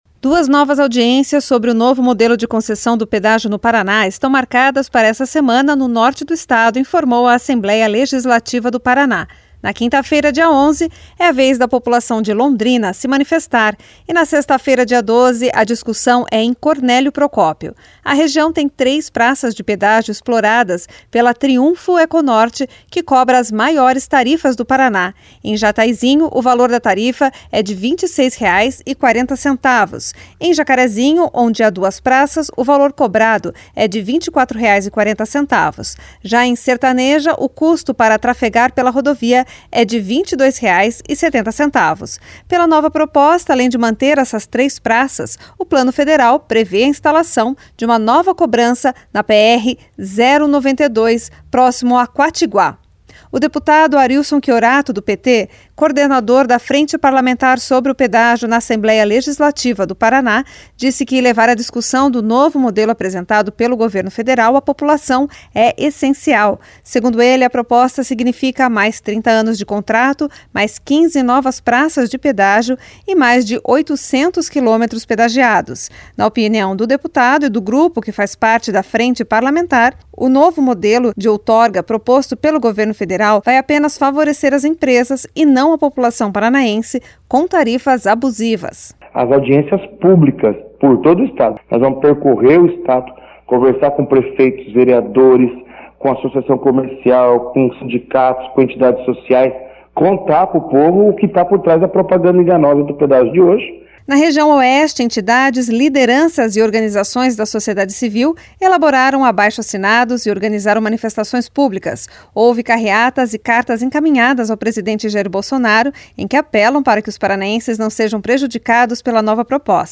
O deputado Arilson Chiorato (PT), coordenador da Frente Parlamentar sobre o Pedágio na Assembleia Legislativa, disse que levar a discussão do novo modelo apresentado pelo governo federal à população é essencial.